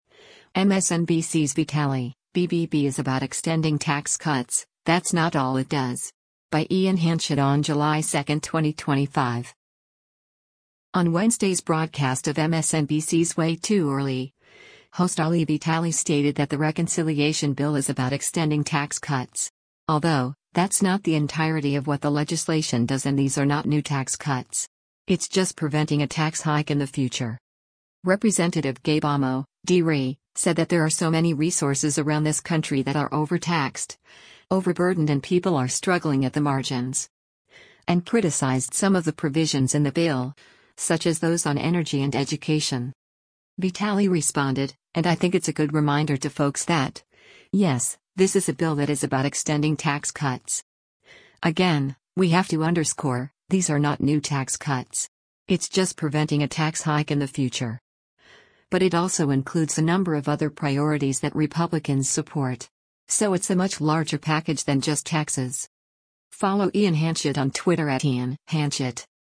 On Wednesday’s broadcast of MSNBC’s “Way Too Early,” host Ali Vitali stated that the reconciliation bill “is about extending tax cuts.”
Rep. Gabe Amo (D-RI) said that there are “so many resources around this country that are overtaxed, overburdened and people are struggling at the margins.” And criticized some of the provisions in the bill, such as those on energy and education.